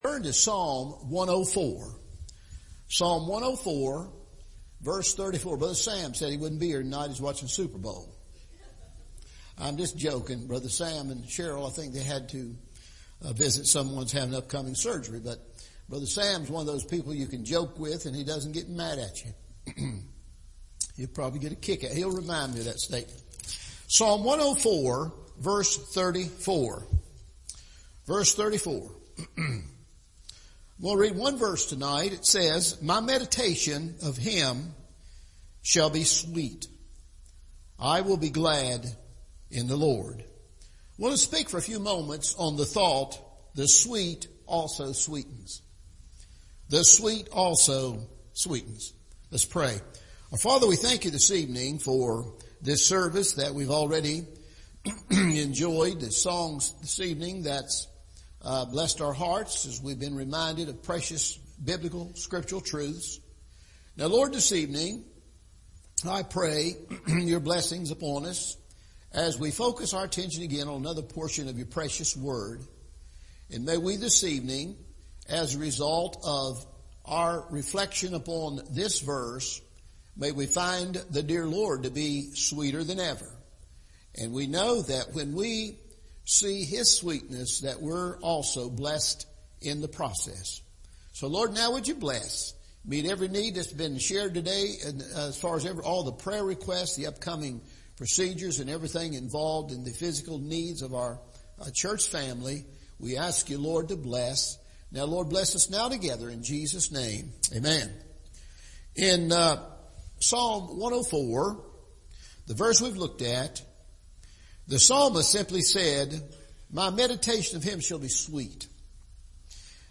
The Sweet also Sweetens – Evening Service